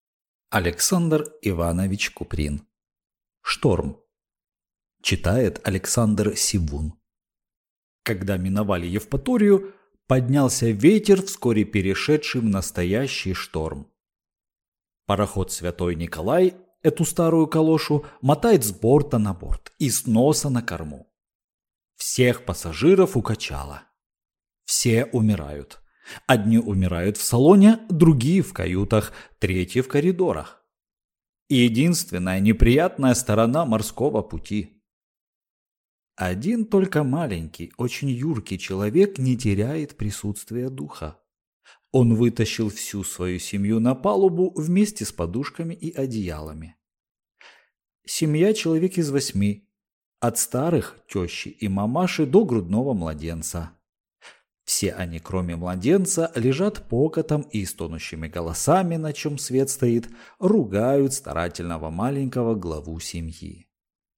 Аудиокнига Шторм | Библиотека аудиокниг